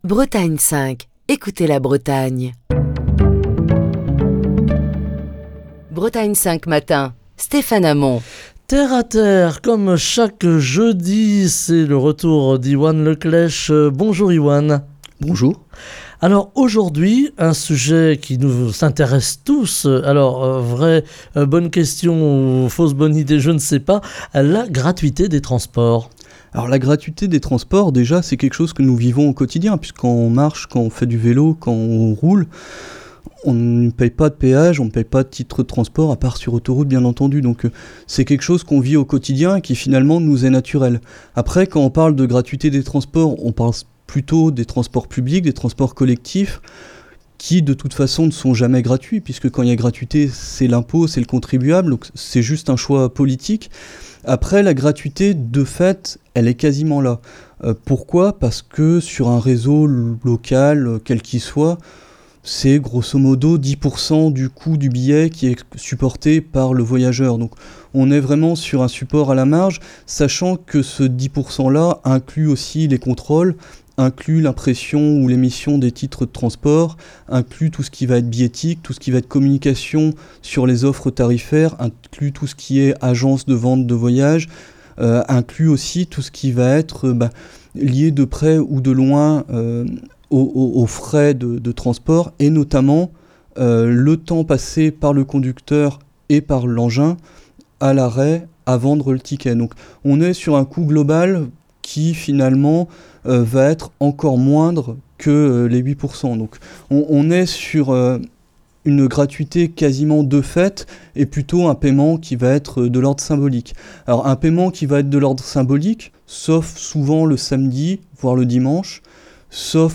Chronique du 27 octobre 2022. La gratuité des transports en commun est depuis quelques années un choix politique en matière de transports publics.